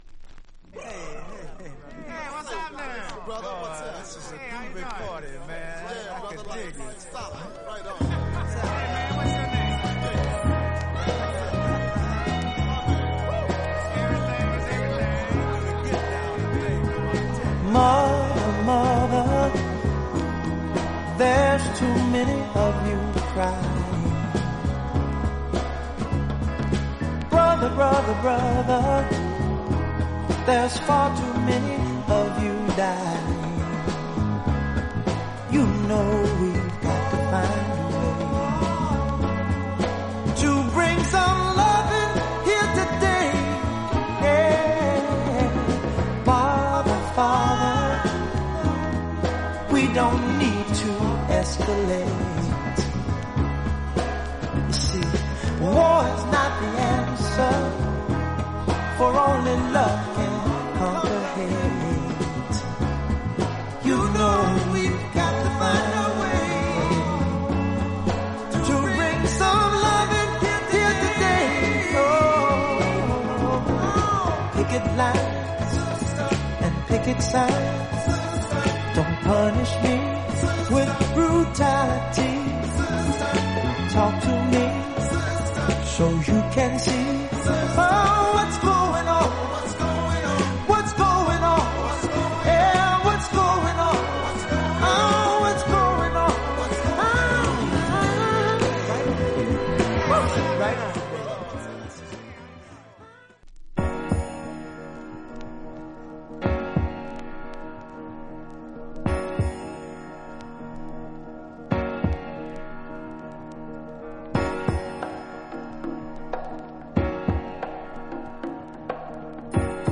実際のレコードからのサンプル↓